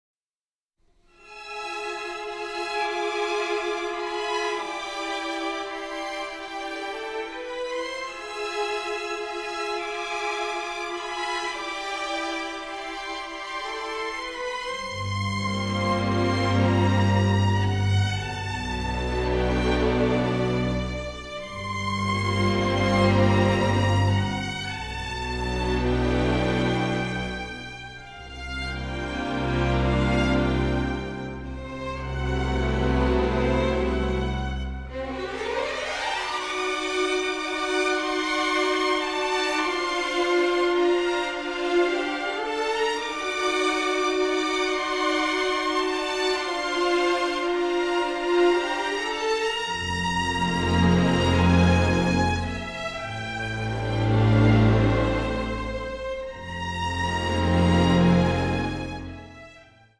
suspense score